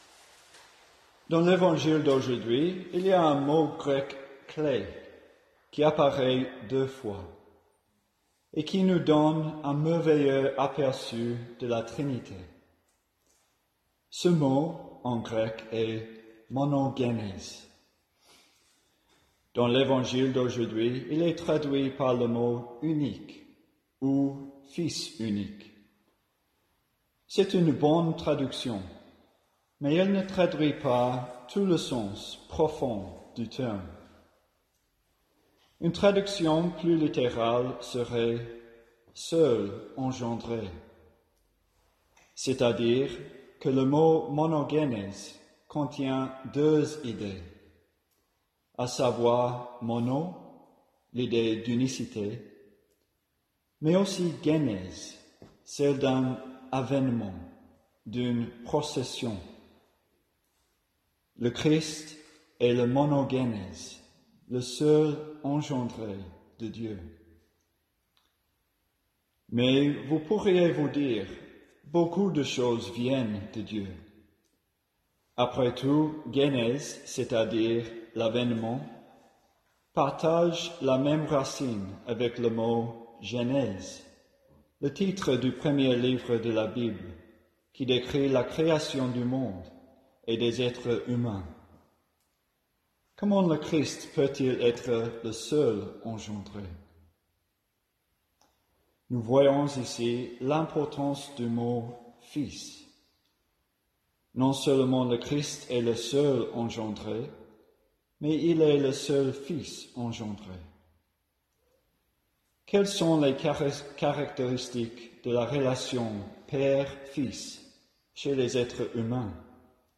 En ce dimanche de la Sainte Trinité, les frères ont célébré la messe à huis clos et ont contemplé ensemble la foi de l'Église en un seul Dieu en trois Personnes Divines.
a présidé la messe et a prêché une homélie calme et bien ordonnée.